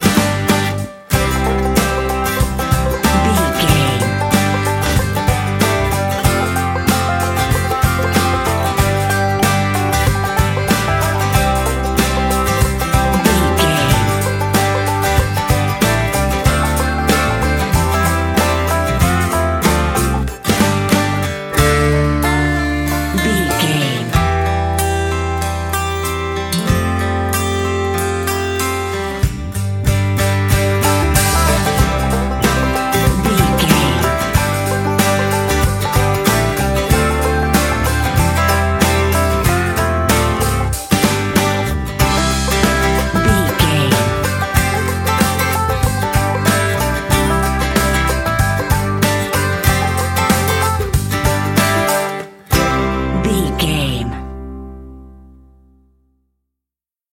Ionian/Major
acoustic guitar
banjo
bass guitar
drums
Pop Country
country rock
bluegrass
uplifting
driving
high energy